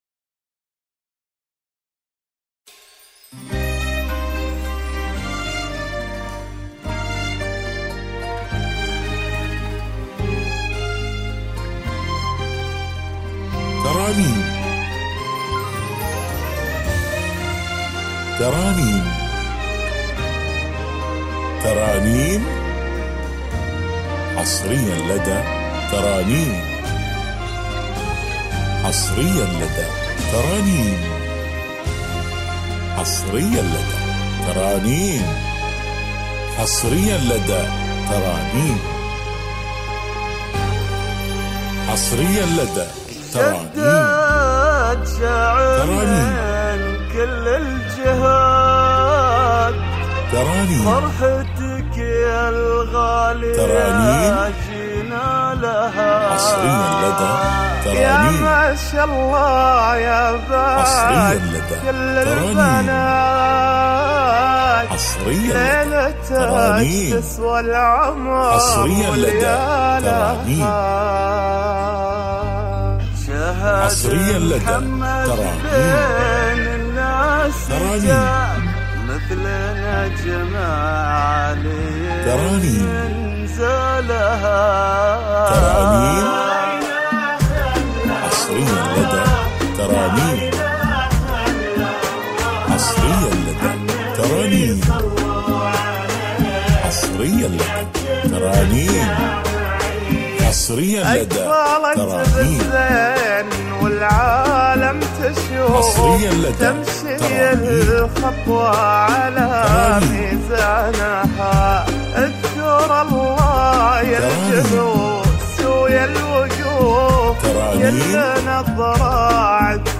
زفات